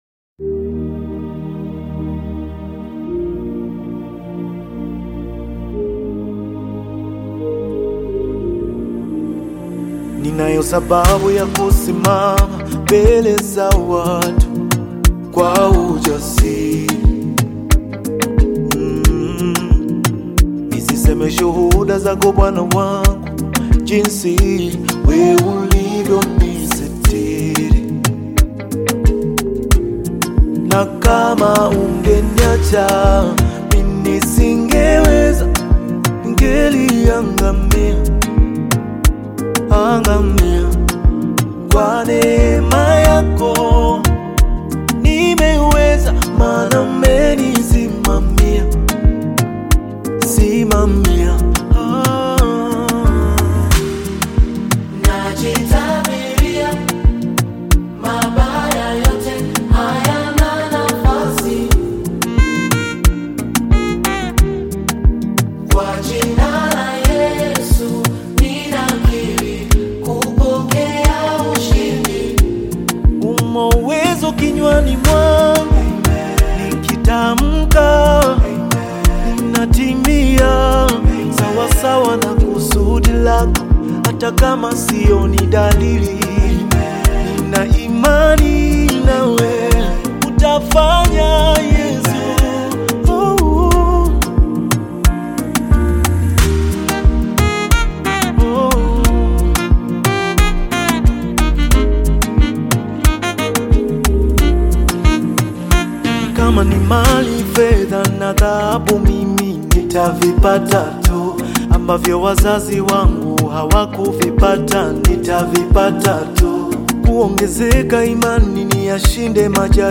gospel song
gospel song for thanksgiving
African Music